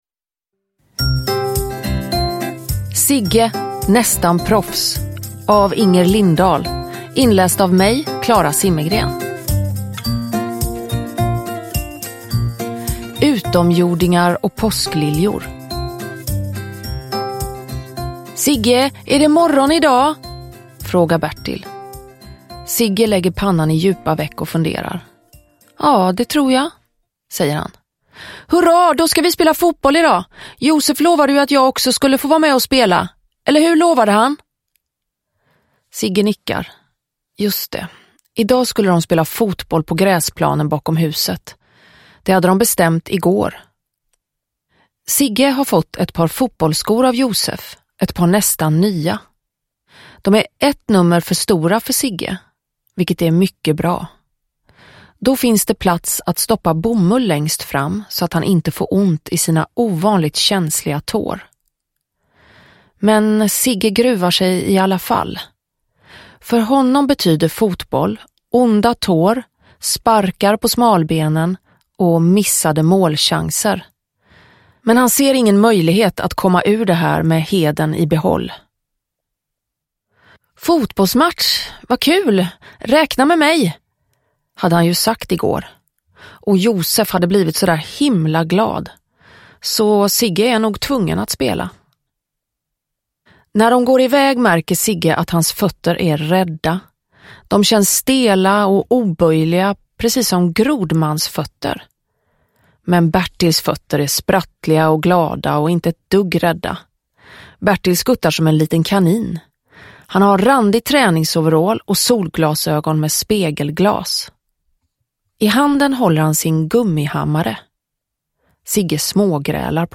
Zigge, nästan proffs – Ljudbok
Uppläsare: Klara Zimmergren